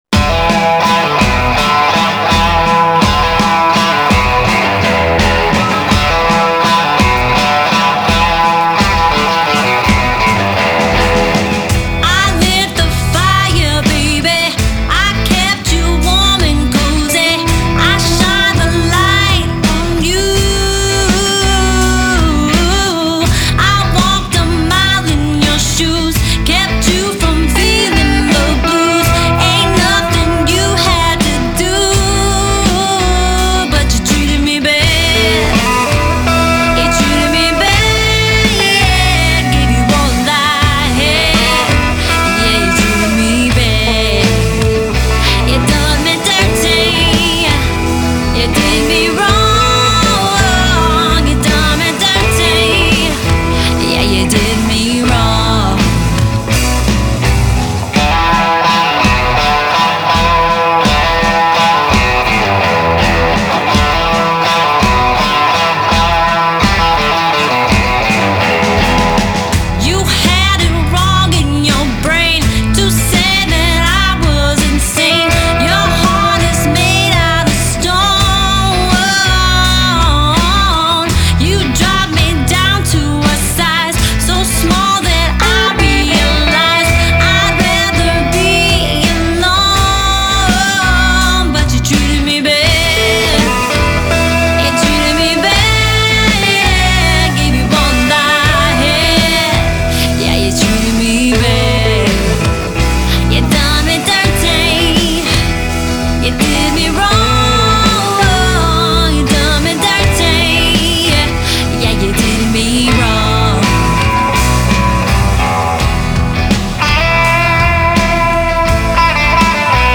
Killer Vocals, Killer Guitar, Killer Rock !!
Genre: Blues, Blues Rock